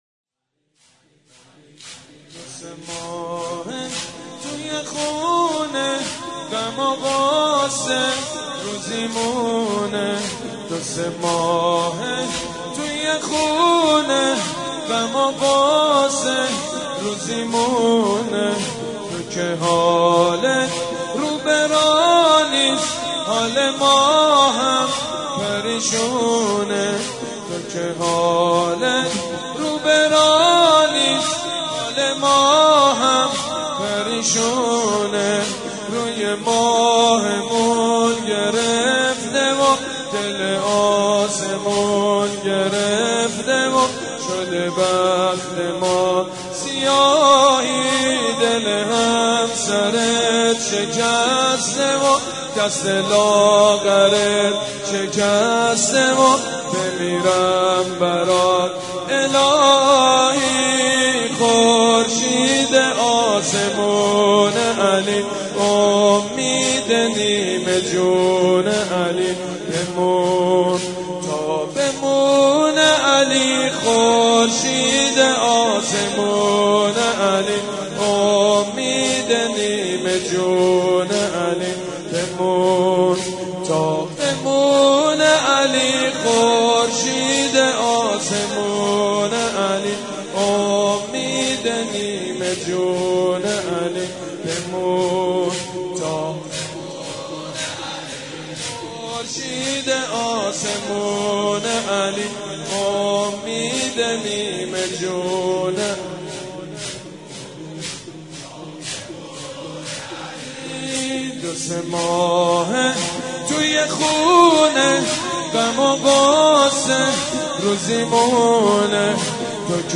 مداحی و نوحه
[سینه زنی شور]